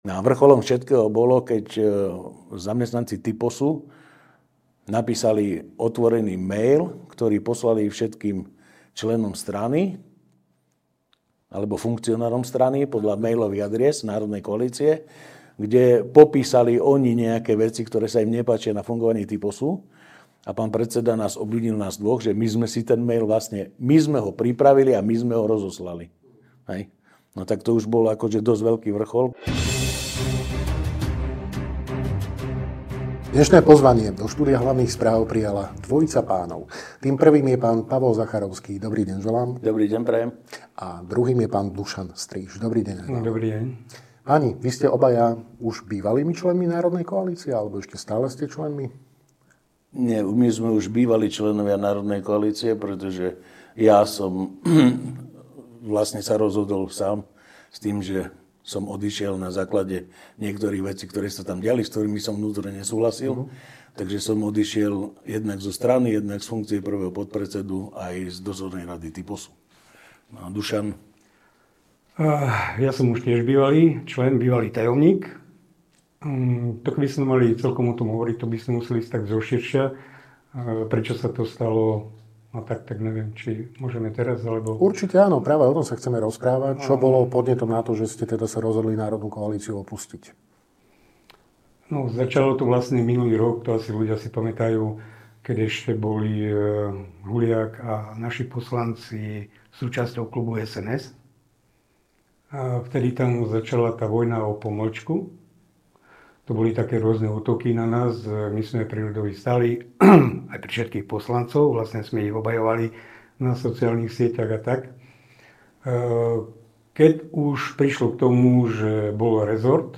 Viac v rozhovore.